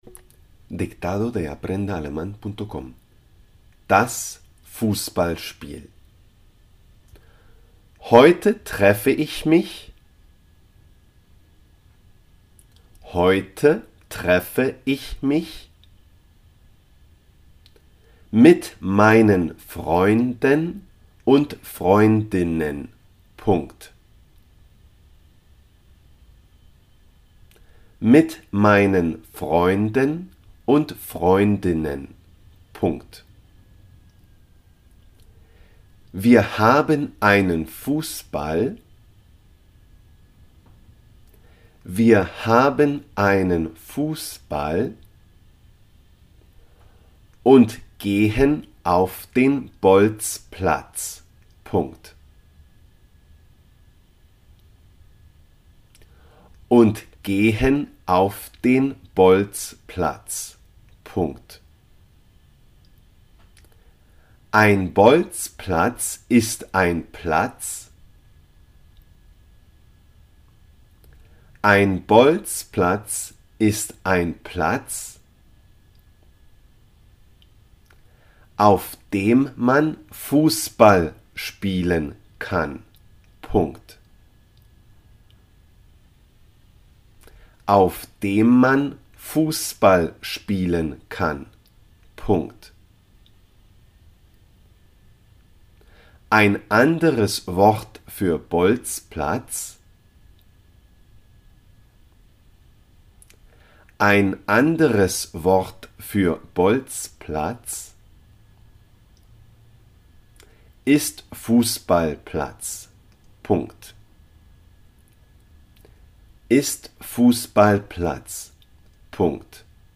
das Fußballspiel – el partido de fútbol 5 .dictado
el-partido-de-futbol-das-Fusballspiel-dictado-en-aleman-1-1.mp3